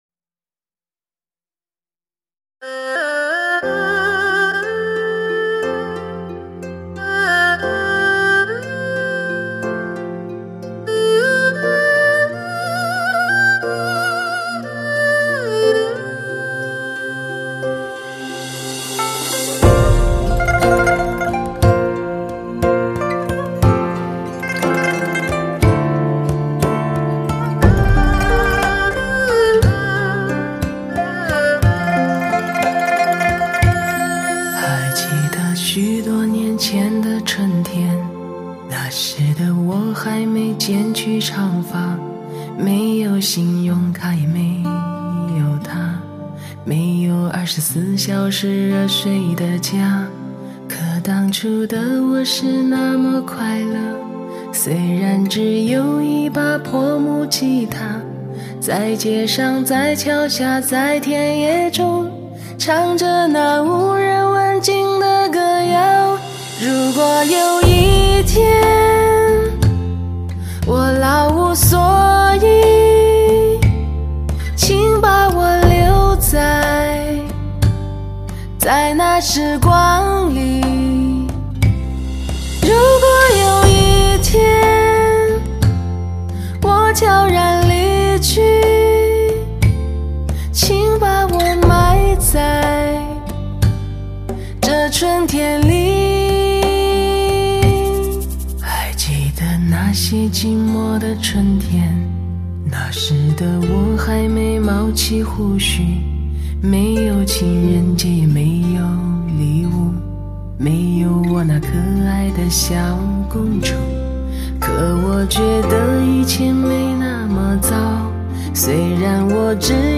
首张钻石女声母盘精选集 限量发行